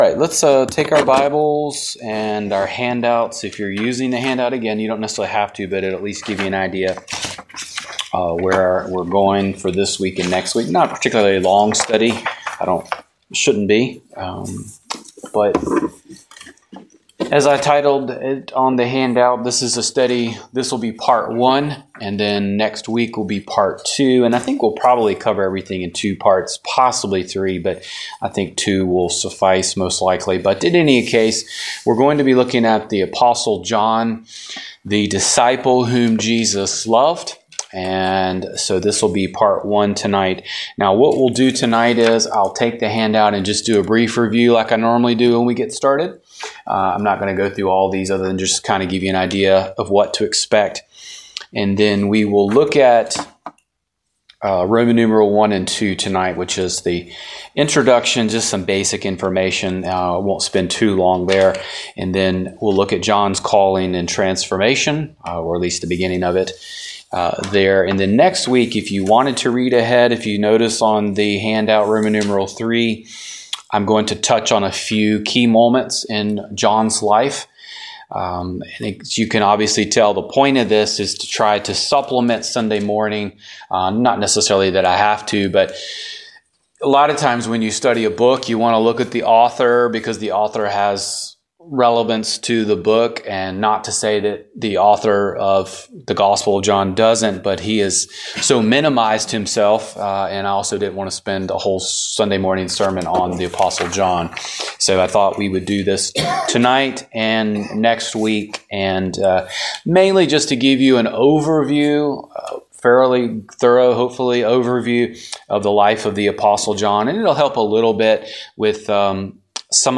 Download Download Life of the Apostle John - Part 1 Wed. Night Bible Study The Colossian Hymn - Part 1 Wed.